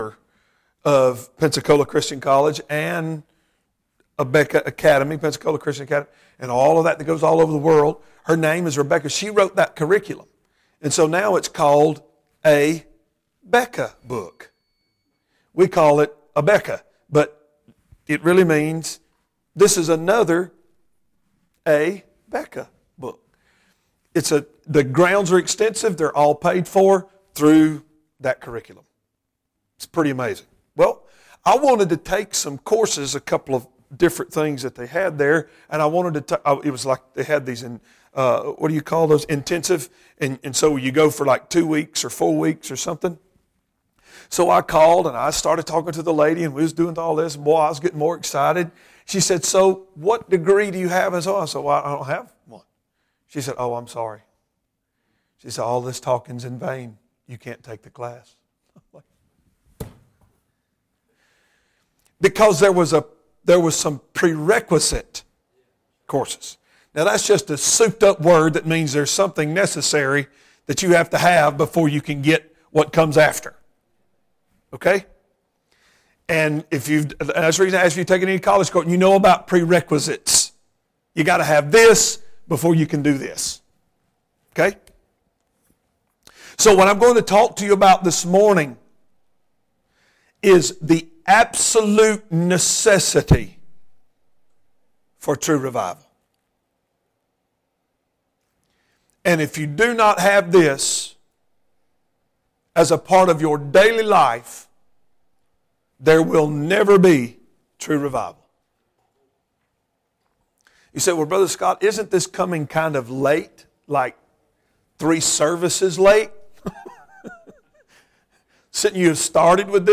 Matthew 3:1-2 Service Type: Sunday Morning %todo_render% « The “Cost” of True Revival The result of rejecting revival.